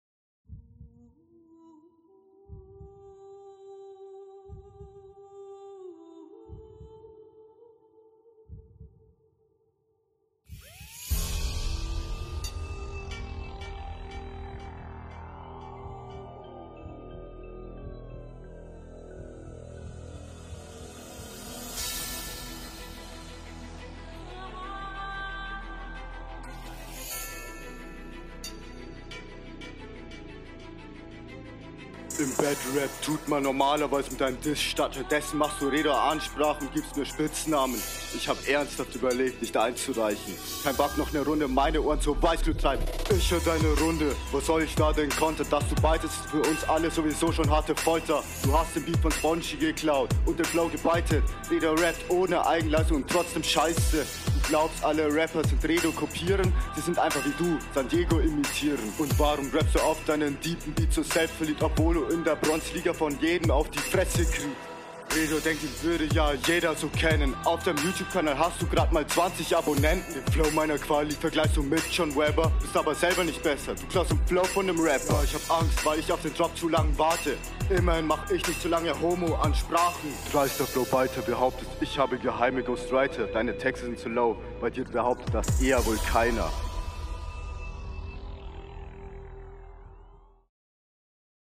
Du stolperst echt doll auf dem Beat rum und ich will gar nicht wissen, was …
Leider selten on beat.